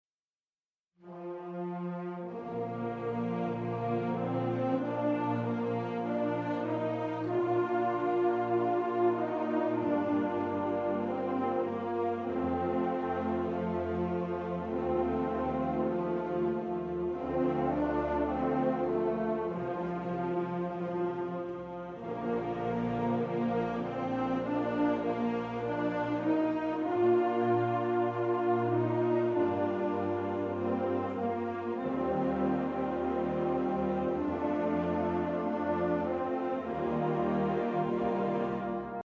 Click below to hear an excerpt of The Pride of West Virginia's version of Simple Gifts